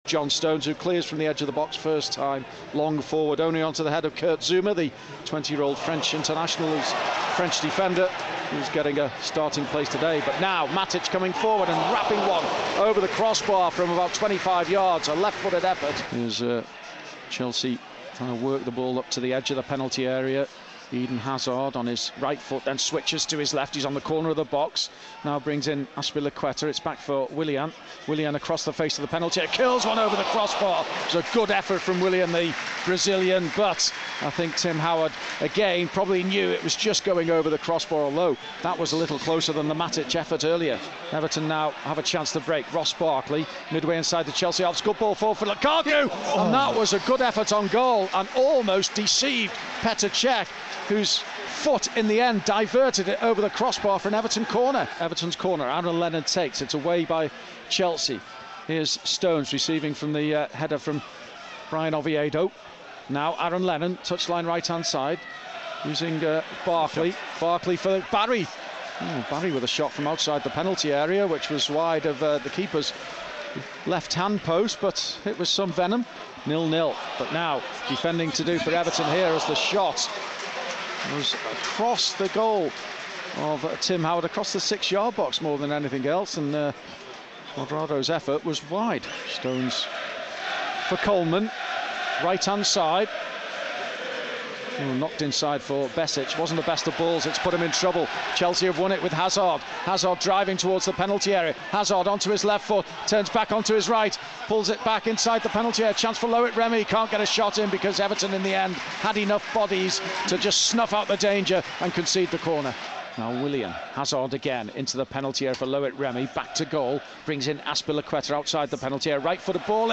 Full match highlights from Stamford Bridge